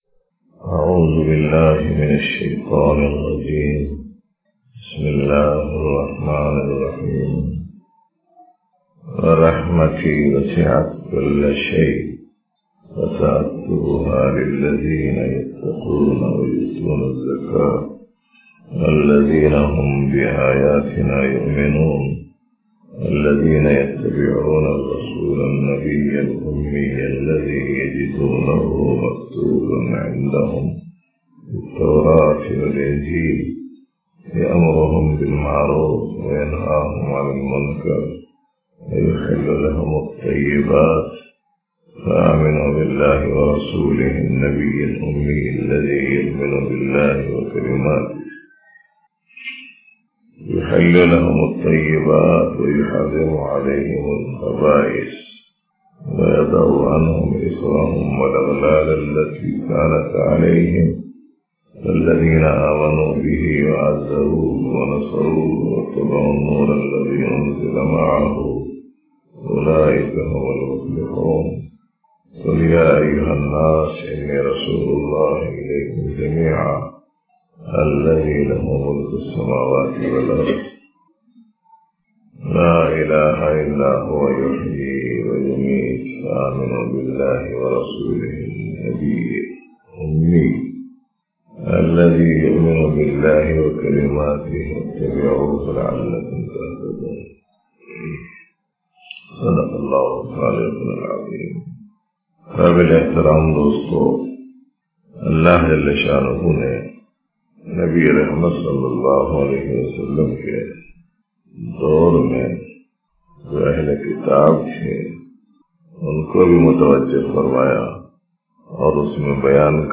Dars e Tasawwuf in Itikaf